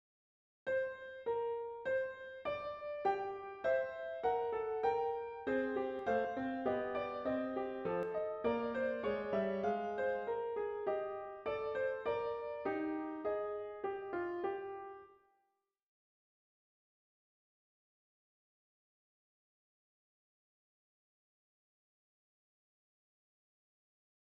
Voici donc le premier passage en strette.